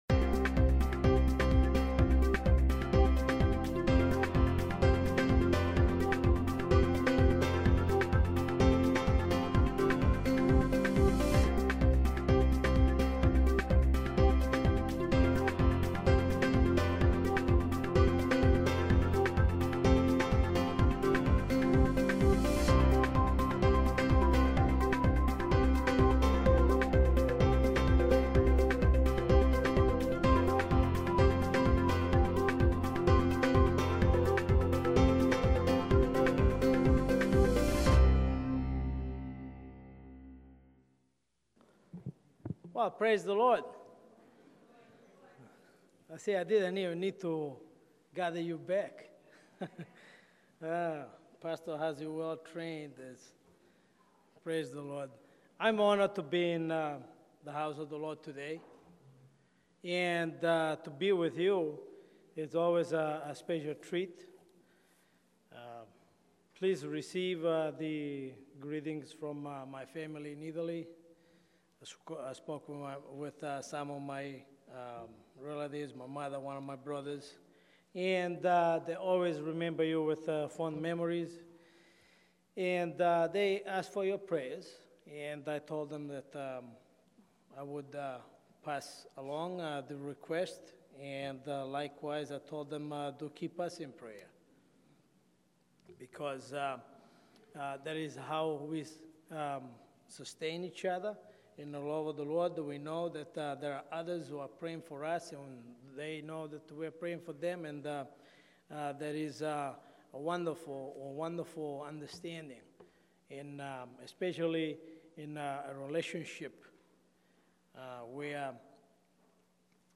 Pentecost Sunday – Trinity Church | Derry, NH 03038